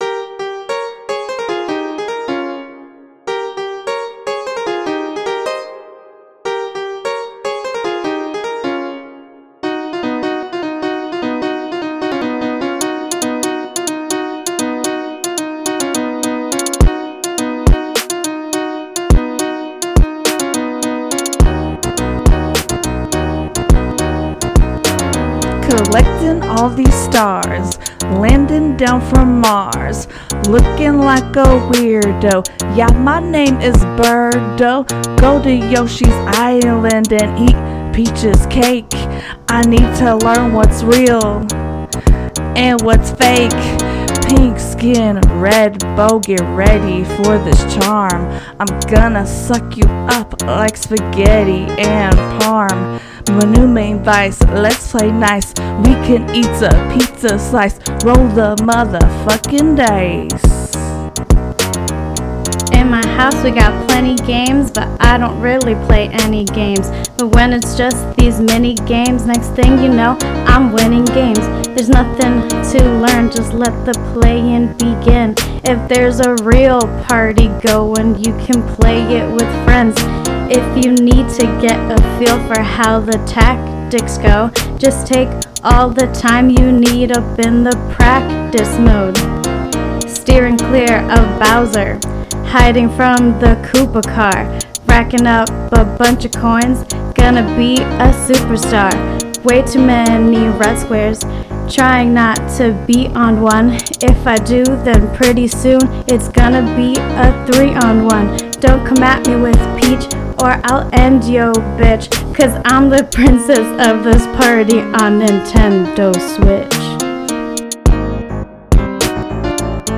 Rap from Episode 49: Mario Party Superstars – Press any Button
Mario-Party-Superstars-Rap.mp3